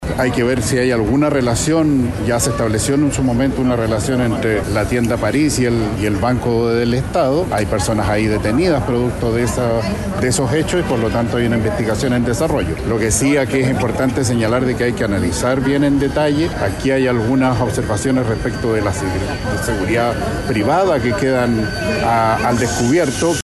Por otro lado, el delegado Presidencial Regional, Yanino Riquelme, indicó que hay que investigar si estos hechos tienen alguna relación con los robos registrados en BancoEstado y en la tienda Paris, ocurridos en marzo y junio pasado, respectivamente.
cu-banco-itau-delegado.mp3